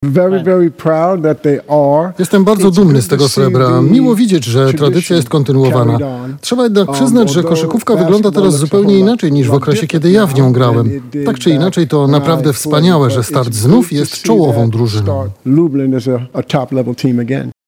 Legenda Startu Lublin, pierwszy czarnoskóry koszykarz w polskiej lidze Kent Washington spotkał się ze swoimi fanami w lubelskim Centrum Historii Sportu.